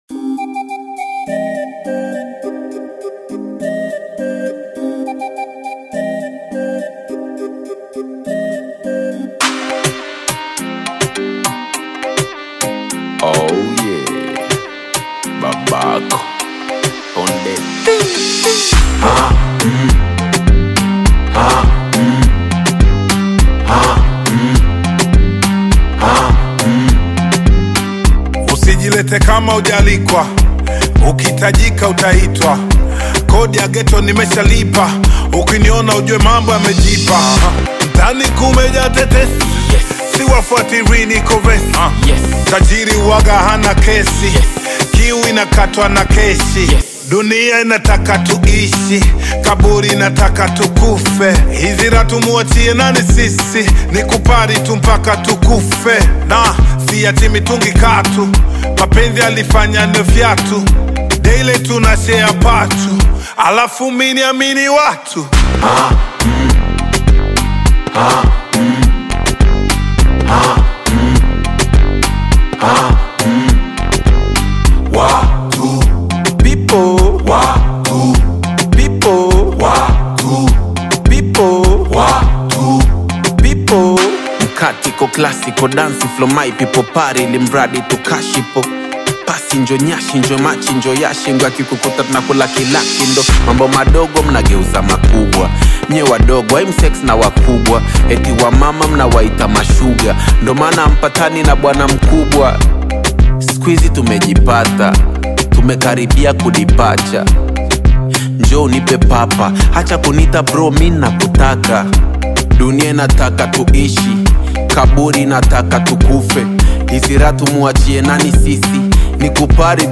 brings melodic expression and emotional depth